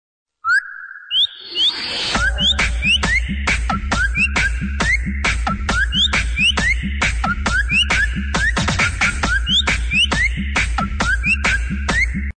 Whistle Mix